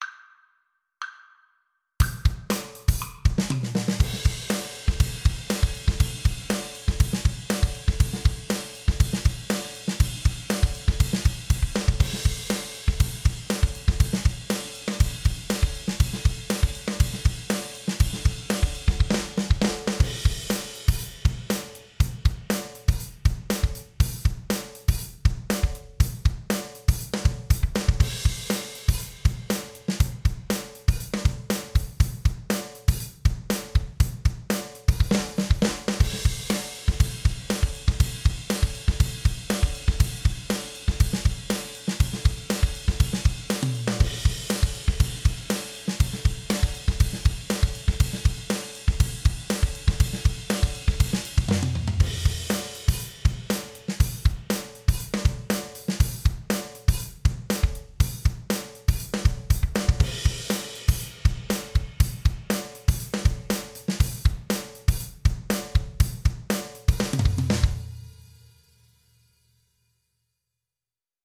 4/4 (View more 4/4 Music)
C5-A6
Violin  (View more Intermediate Violin Music)
Pop (View more Pop Violin Music)